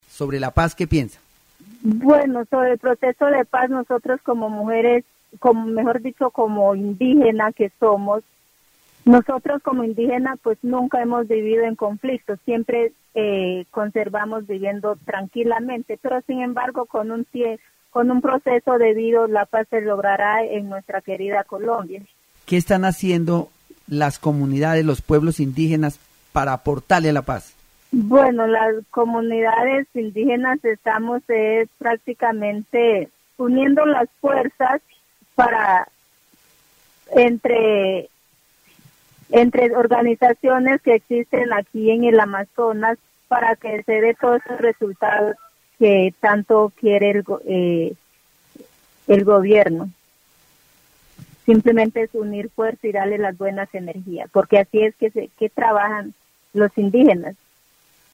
n este segmento del programa "Voces y Regiones", se aborda la percepción de los pueblos indígenas sobre la paz en Colombia. La entrevistada, representante de una comunidad indígena del Amazonas, destaca que su pueblo nunca ha estado en conflicto y ha vivido en armonía. Sin embargo, enfatiza que para alcanzar una paz duradera, es necesario unir fuerzas entre las organizaciones indígenas de la región.
Programas de radio